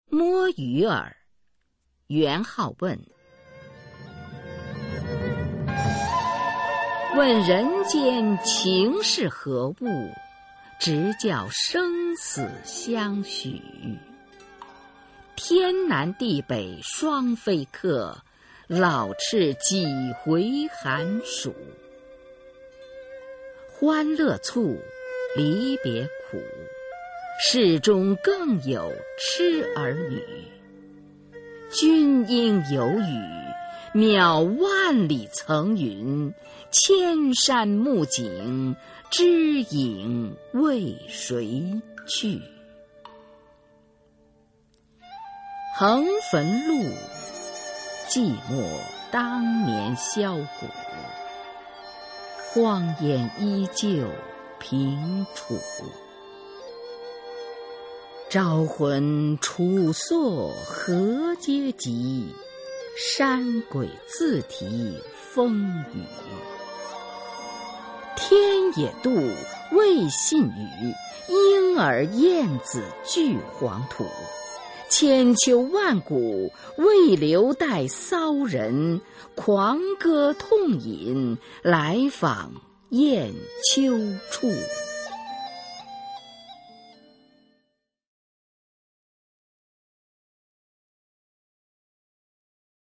[宋代诗词诵读]元好问-摸鱼儿 宋词朗诵